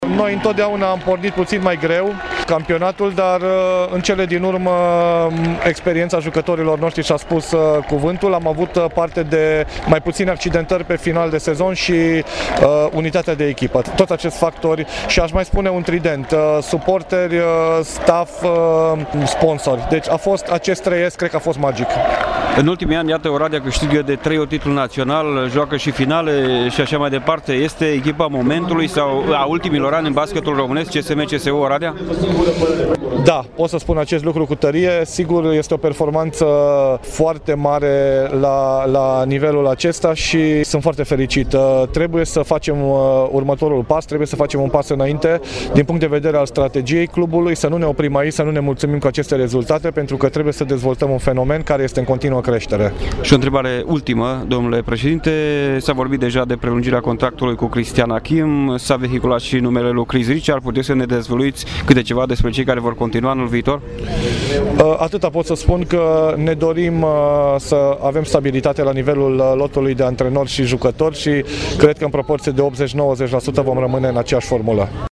Intervievat